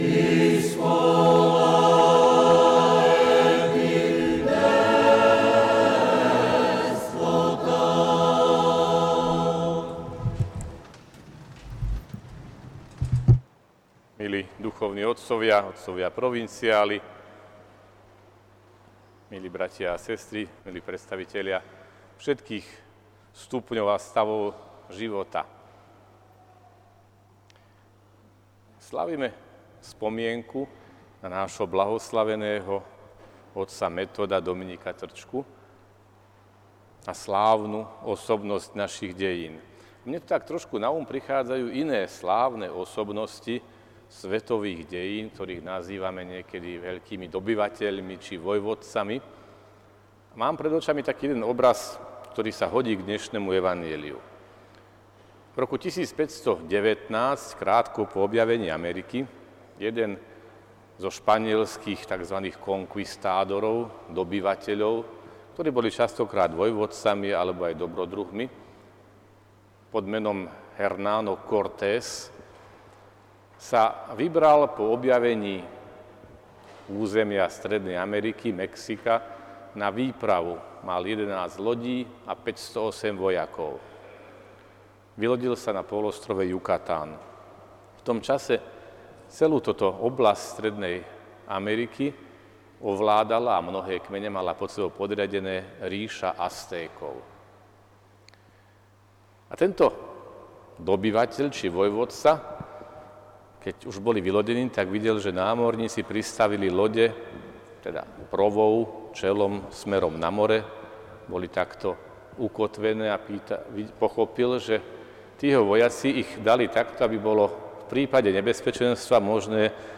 Odpustová slávanosť bl. Metoda 2022 – nedeľa